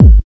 LOW135BD-R.wav